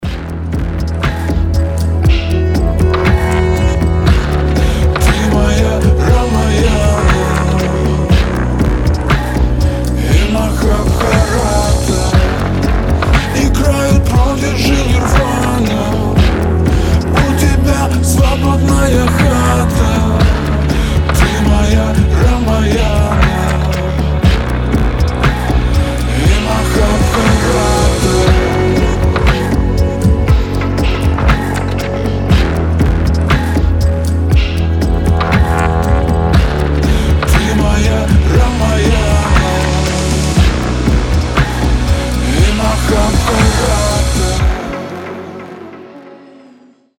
• Качество: 320, Stereo
поп
атмосферные
спокойные
романтичные
расслабляющие
индийские мотивы